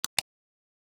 Click (9).wav